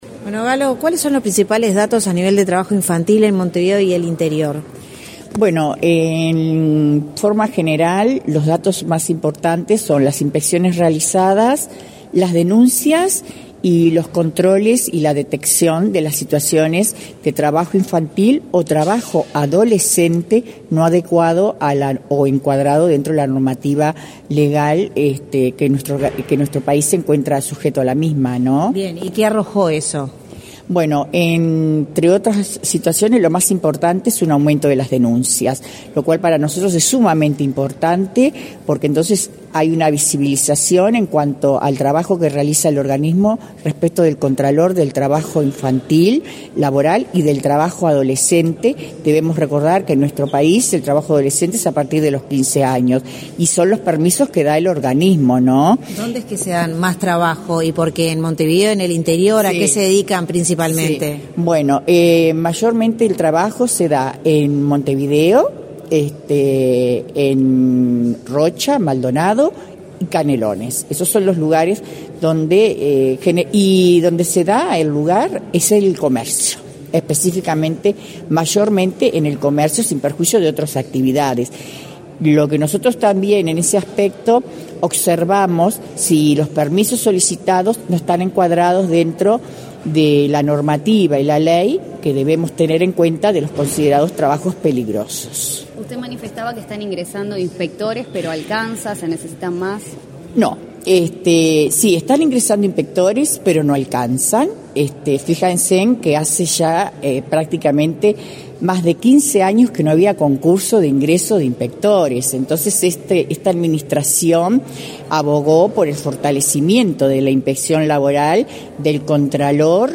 Declaraciones a la prensa de la directora general del INAU, Dinorah Gallo
Tras el evento, la directora general del INAU, Dinorah Gallo, realizó declaraciones a la prensa.